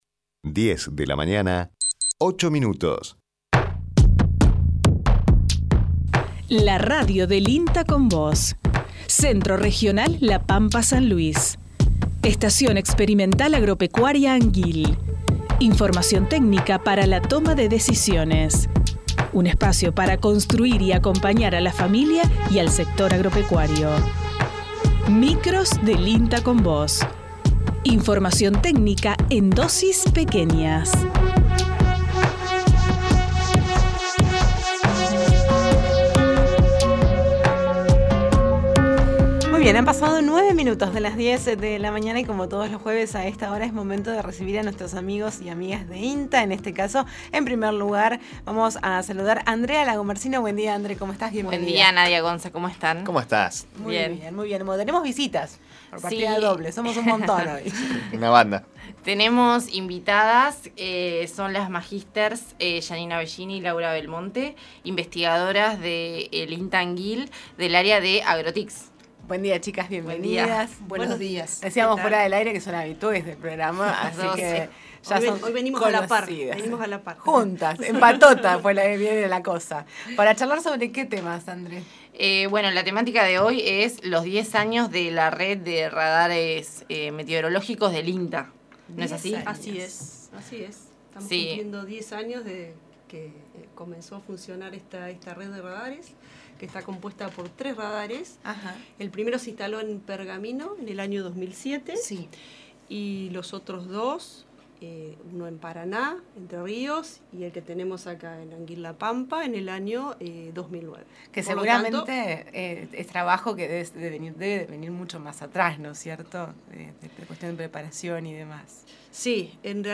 Location Santa Rosa, La Pampa, Argentina